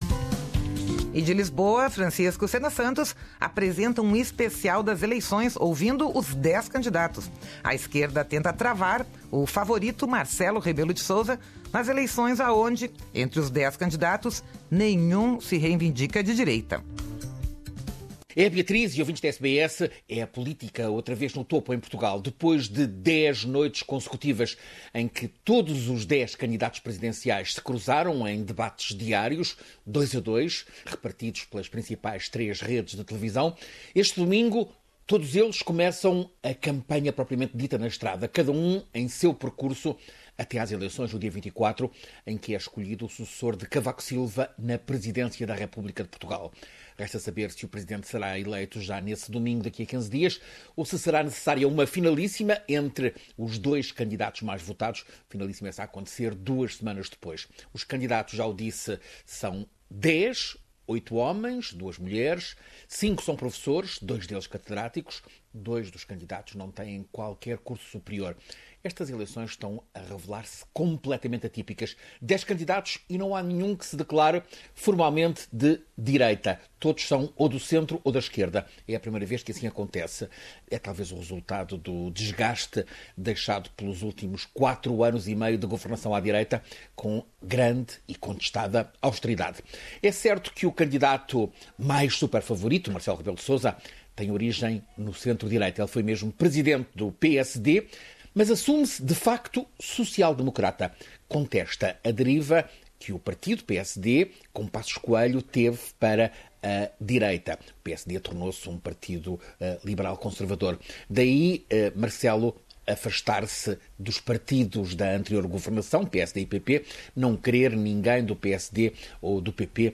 Presidenciais em Portugal: Ouça aqui os dez candidatos